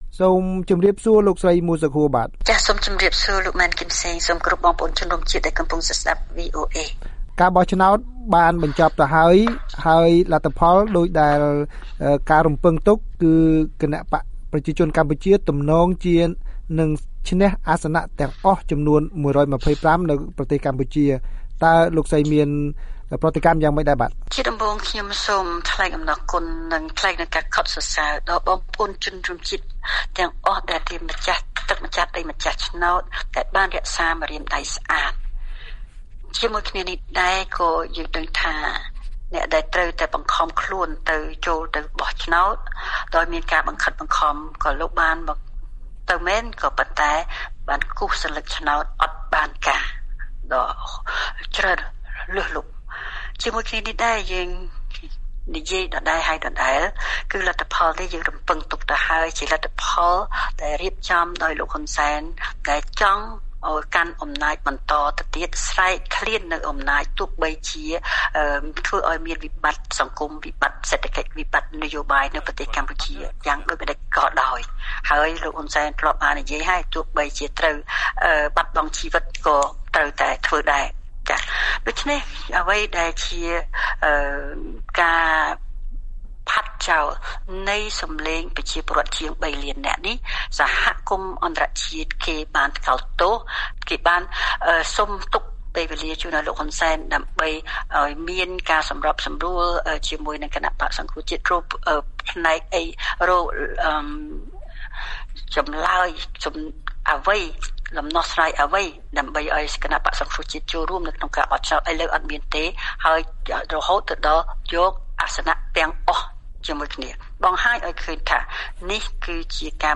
បទសម្ភាសន៍ VOA៖ សង្គ្រោះជាតិបដិសេធលទ្ធផលឆ្នោត ហើយថាគ.ជ.បបន្លំតួលេខអ្នកទៅបោះឆ្នោត